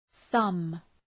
Προφορά
{ɵʌm}
thumb.mp3